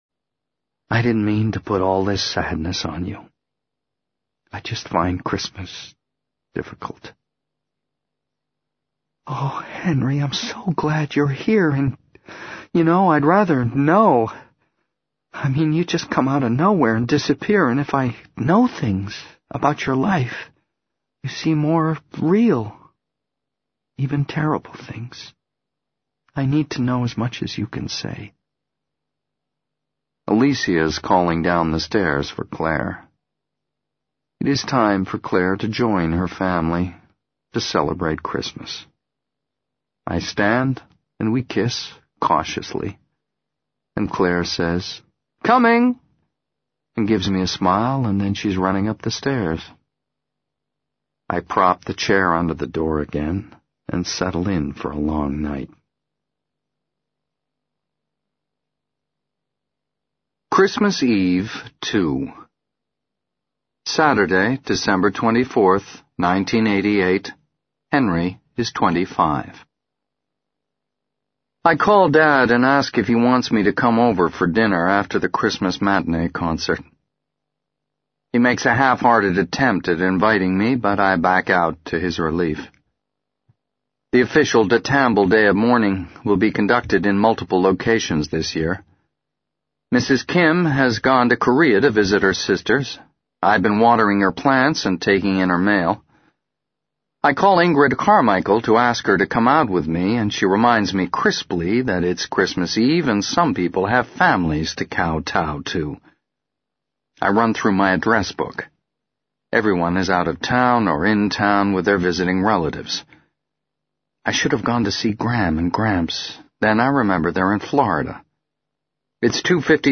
在线英语听力室【时间旅行者的妻子】96的听力文件下载,时间旅行者的妻子—双语有声读物—英语听力—听力教程—在线英语听力室